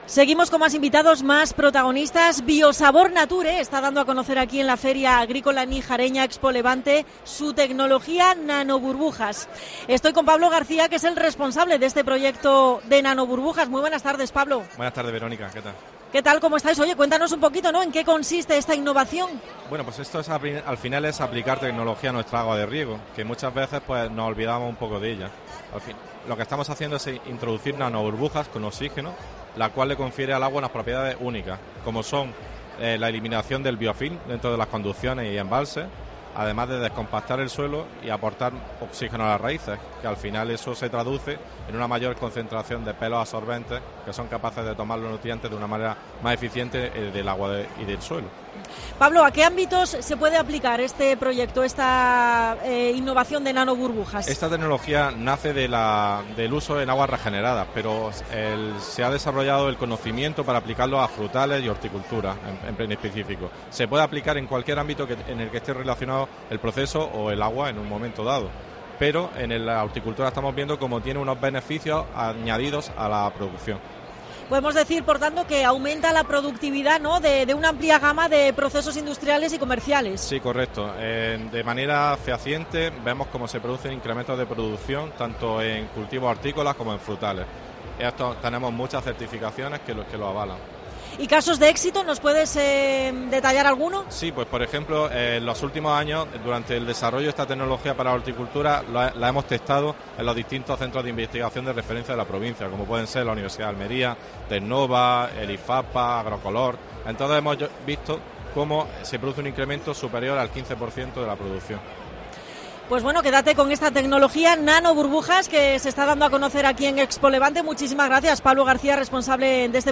Especial COPE Almería desde ExpoLevante en Níjar.